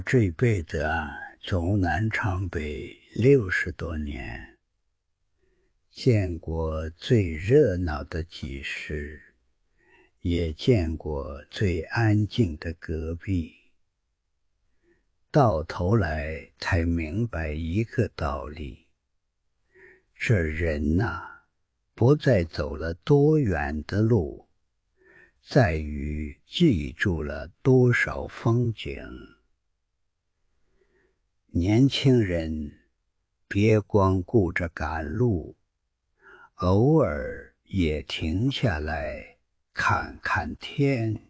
此外，小米官方给出了一段示例，其提示次是“一位年迈的老先生，说带北方口音的普通话，语速缓慢而沉稳，嗓音略带沙哑和沧桑感，仿佛一位饱经风霜的老爷爷在讲故事，充满岁月的智慧”。